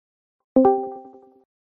join_call-B65a9Ev2.mp3